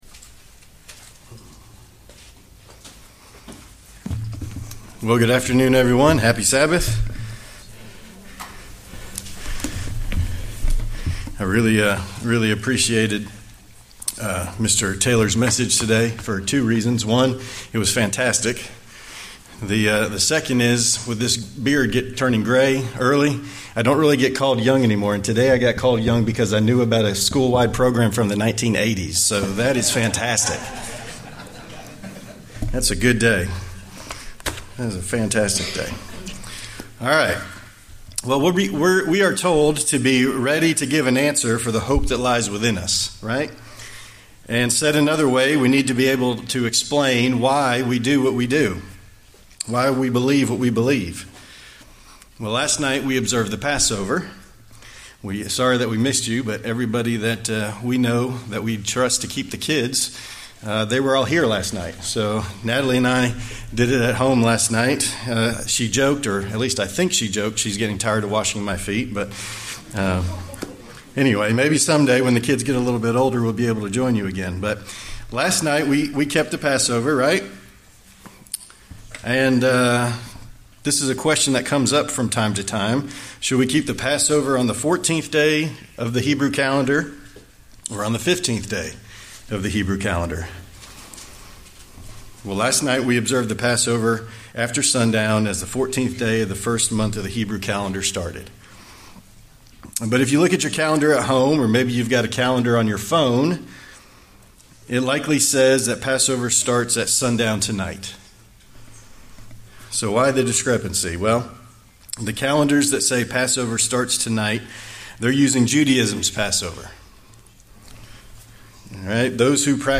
What does the bible say about this topic? In this sermon we will examine the timing of the Passover in Exodus, and the new testament.
Given in Huntsville, AL